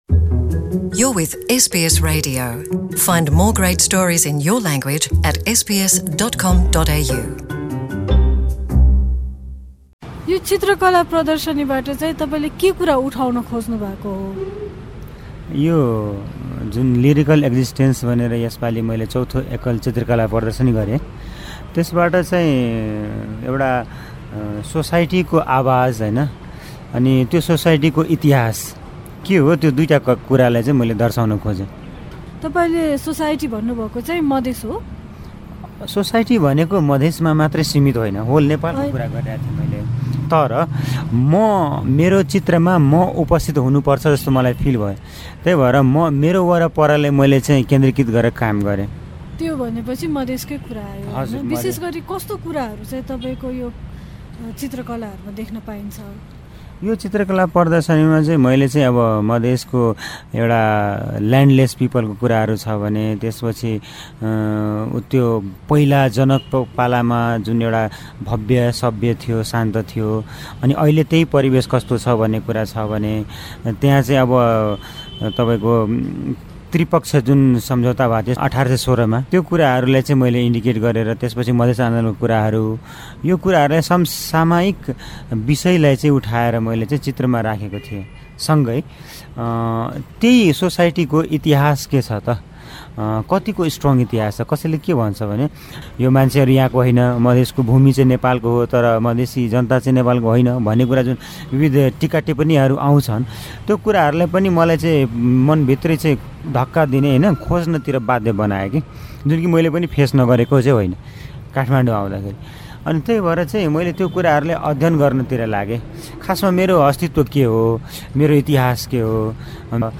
उहाँसँग गरिएको हाम्रो कुराकानी सुन्न माथि रहेको मिडिया प्लेयरमा प्ले बटन थिच्नुहोस् कुराकानीको प्रमुख अंश: तपाईँले देखाउन खोज्नु भएको समाजचाहिँ मधेस हो?